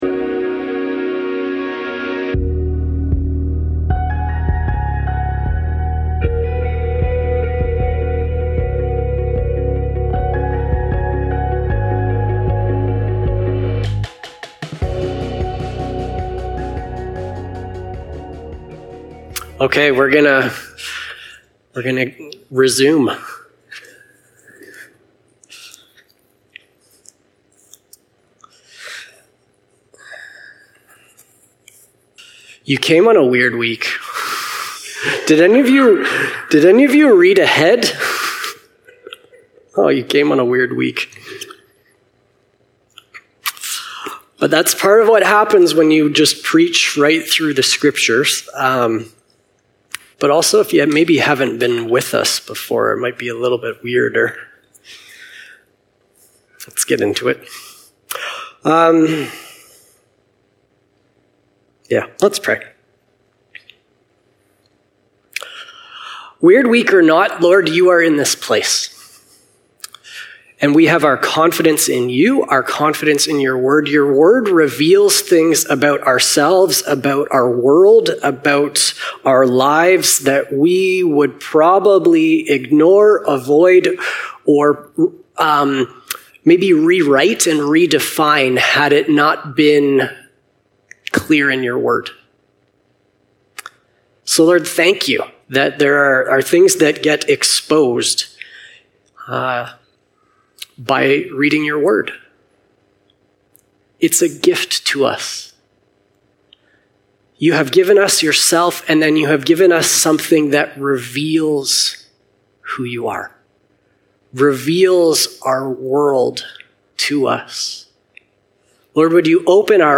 Sermons | Unity Baptist Church